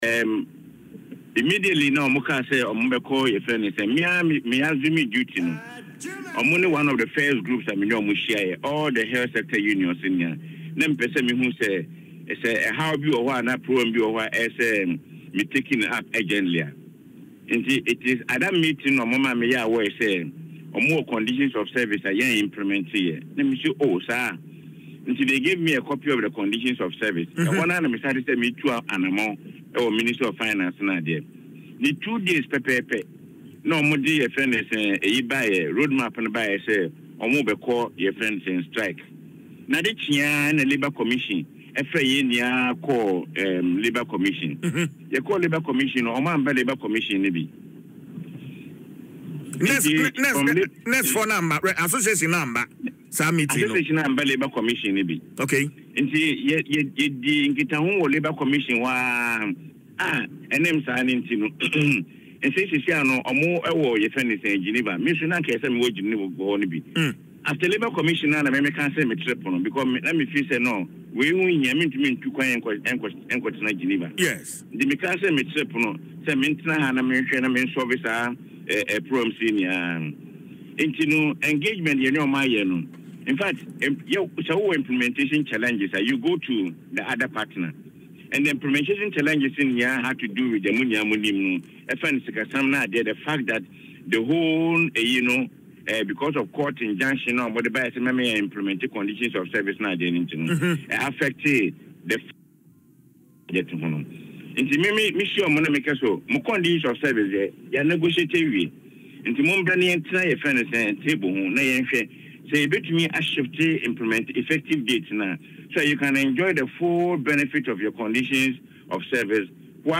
Chief Executive Officer of the FWSC, George Smith-Graham, made the assertion in an interview on Adom FM’s Dwaso Nsem.